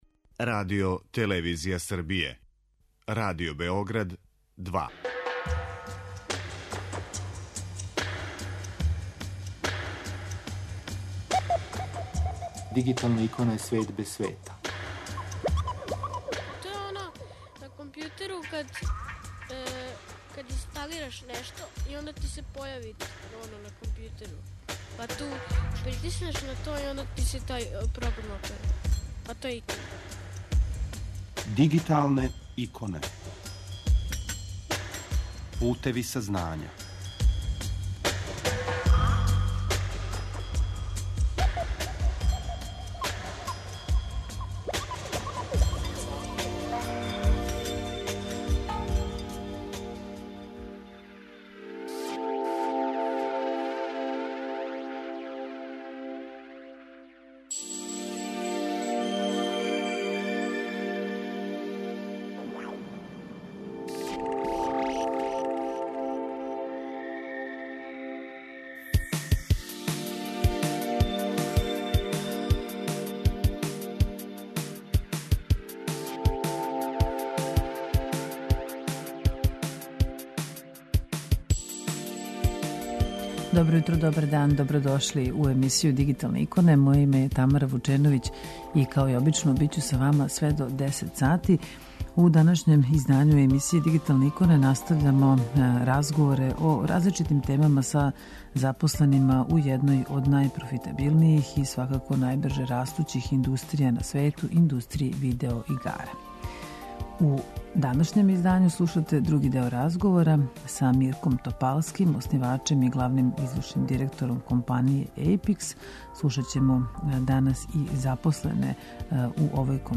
Имаћете прилику да чујете и дизајнере, цртаче, психологе, сценаристе, музичаре, програмере и многе друге који својим, занимљивим али веома напорним радом, "стварају светове" видео-игара.